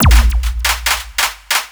TSNRG2 Breakbeat 001.wav